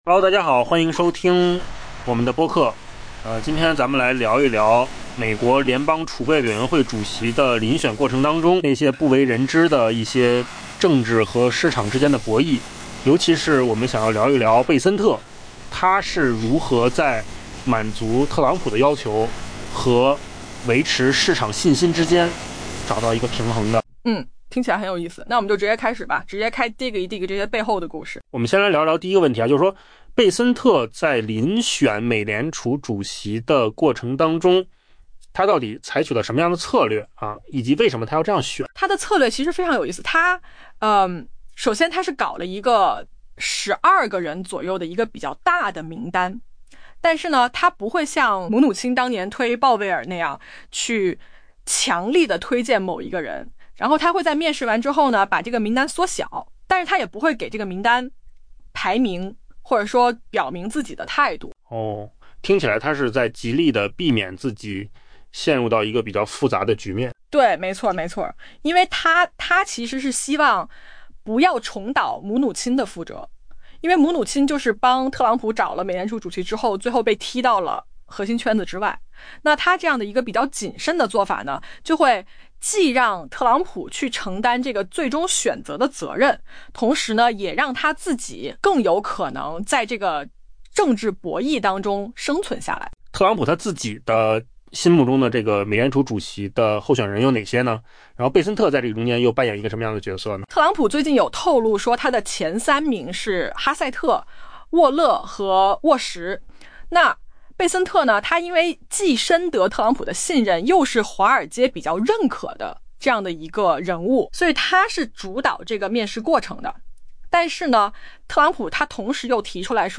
AI 播客：换个方式听新闻 下载 mp3 音频由扣子空间生成 贝森特正刻意地试图避免其前任姆努钦的命运，后者曾是特朗普第一任期内的财长，并努力为特朗普寻找美联储主席，如今却发现自己已被逐出核心圈。